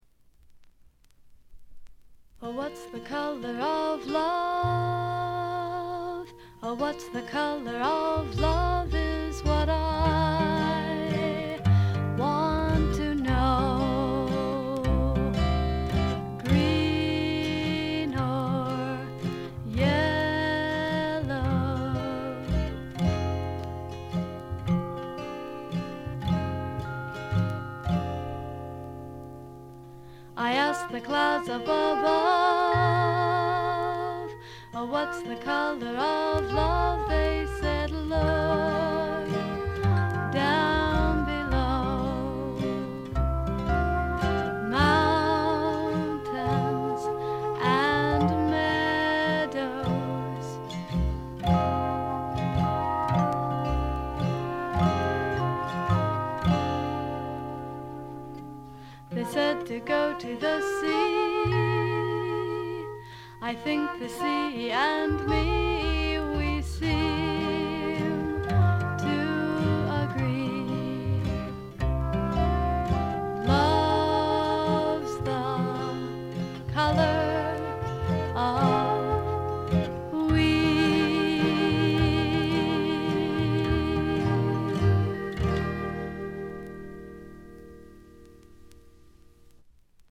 甘酸っぱい香りが胸キュンのまばゆいばかりの青春フォークの傑作。
Vocals, Guitar, Composed By ?